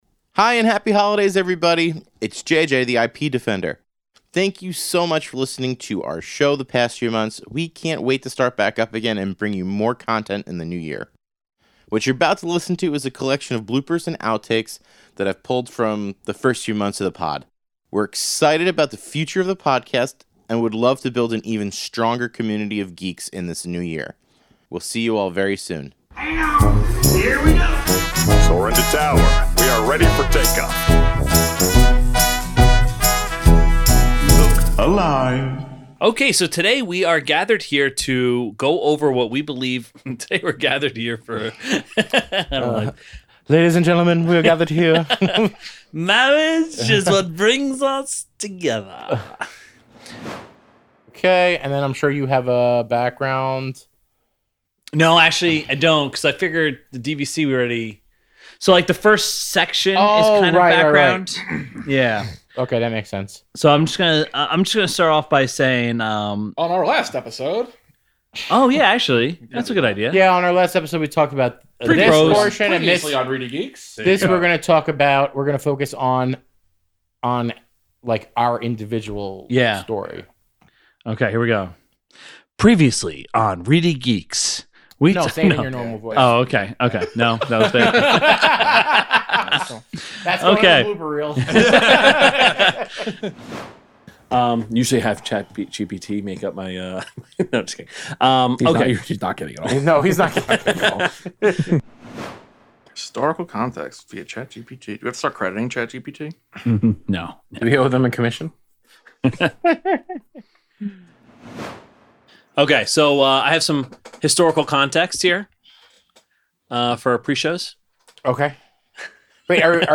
In this episode, the Geeks pull back the curtain and show some of our memorable missteps in this blooper episode.